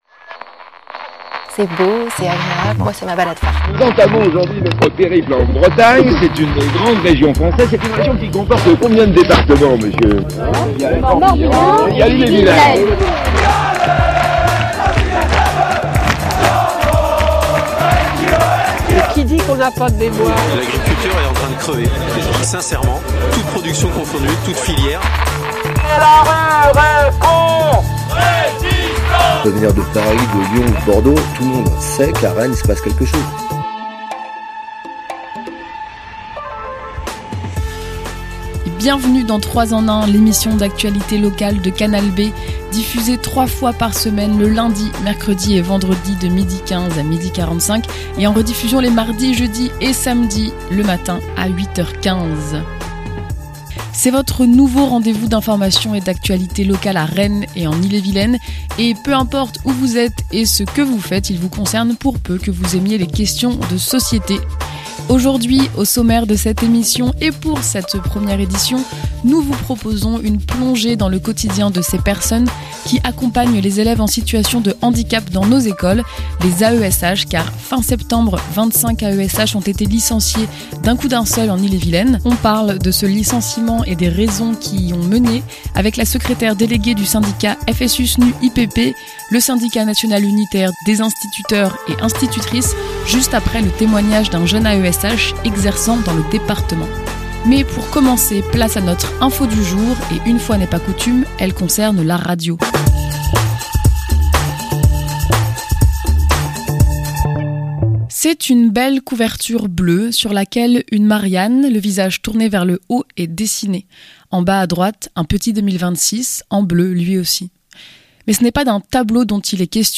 Baisse du Fonds de Soutien à l'Expression Radiophonique locale / Interview et reportage sur le métier d’Accompagnant des élèves en situation de handicap